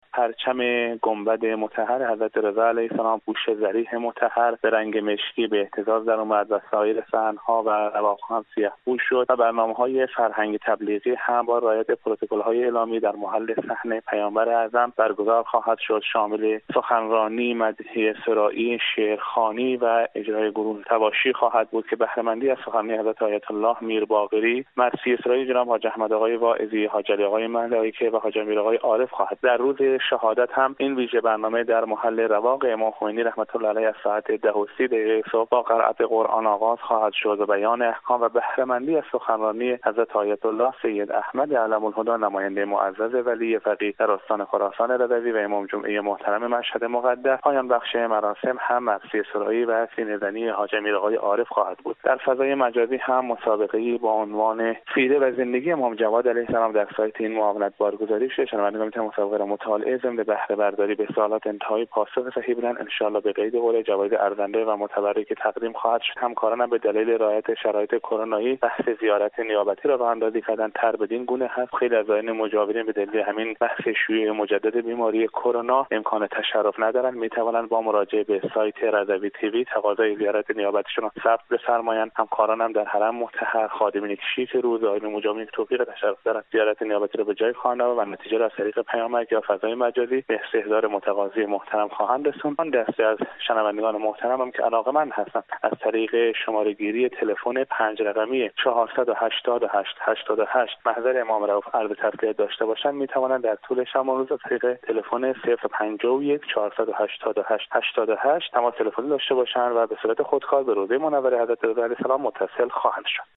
در گفتگو با خبر رادیو زیارت با اعلام این خبر اظهار داشت: